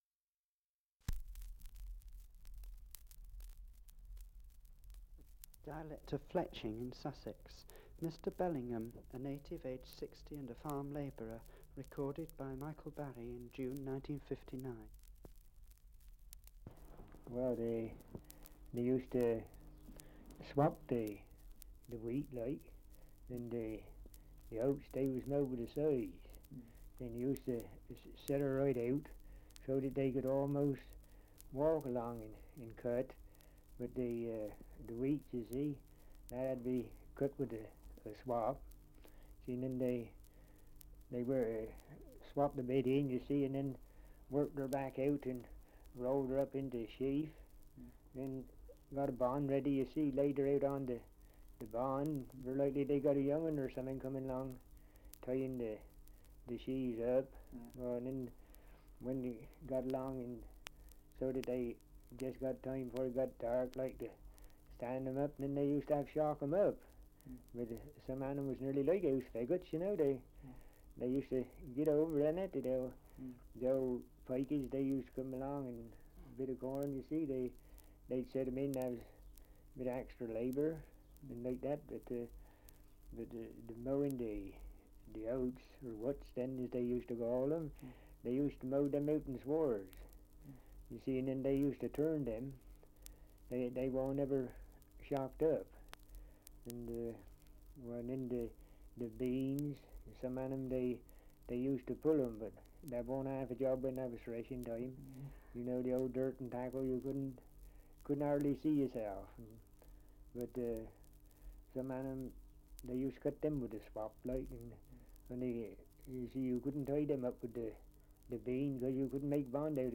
Survey of English Dialects recording in Fletching, Sussex
78 r.p.m., cellulose nitrate on aluminium